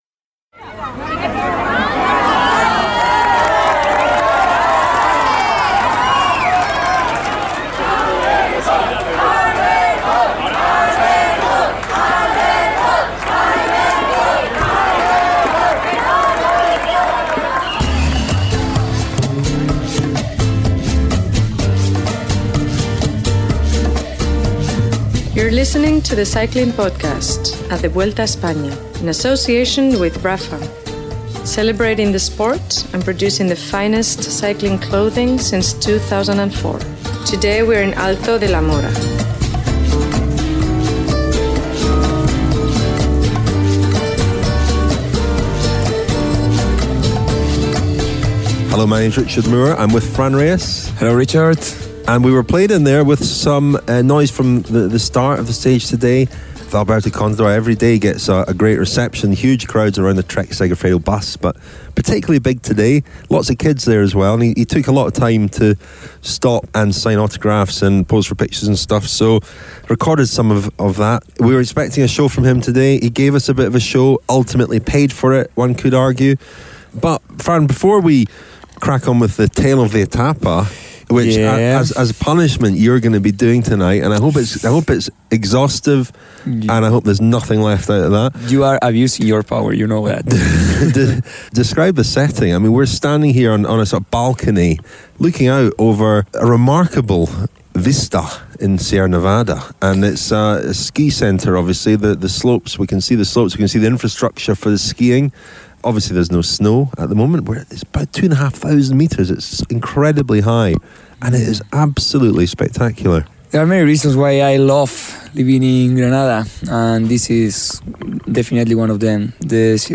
The Cycling Podcast was at the summit and our stage 15 episode includes interviews with some of the stars of the day: Ilnur Zakarin, the Russian who moved up to third overall with a late attack, and Michael Woods, the Canadian who has been one of the revelations of the Vuelta.